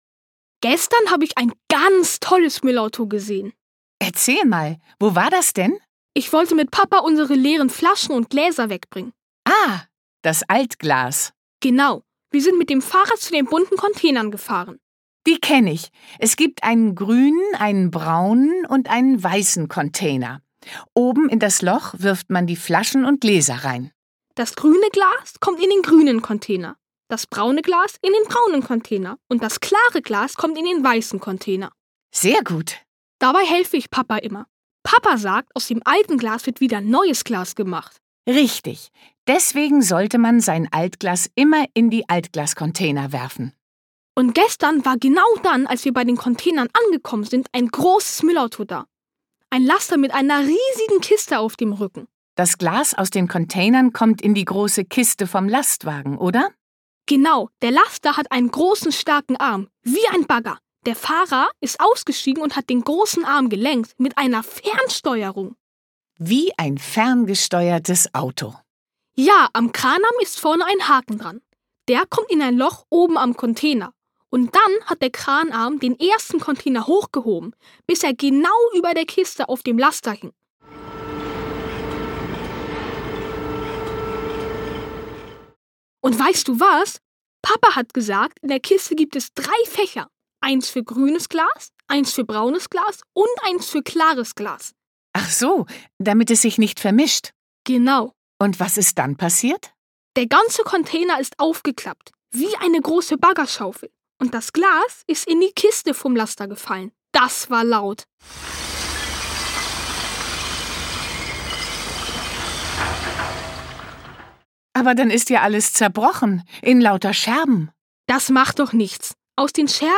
Wenn es vor dem Fenster rumpelt, ist es endlich da: das Müllauto. Im Hörspiel erleben die Kleinen mit, wie der Absetzkipper Bauschutt wegbringt, das Sperrmüllfahrzeug große Gegenstände vom Straßenrand abholt und die Kehrmaschine die Straßen reinigt.